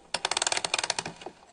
(15.96 KB, wind.ogg)